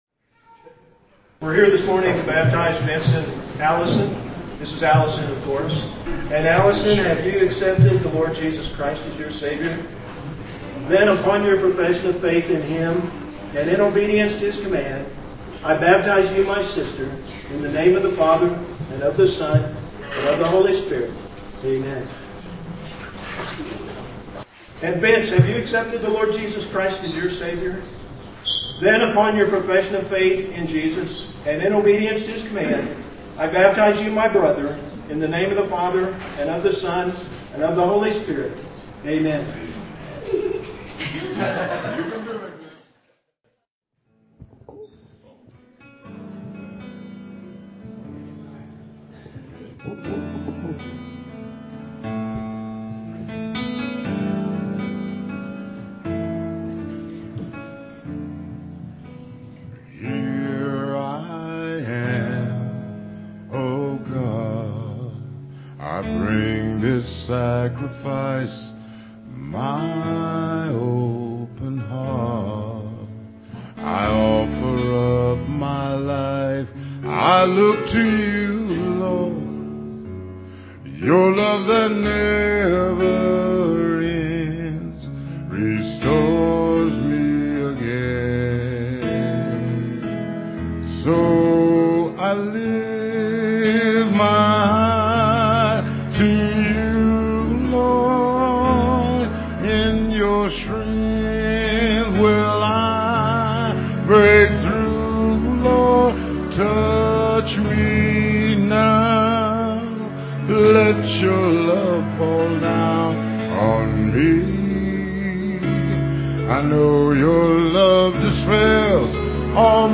Piano-organ duet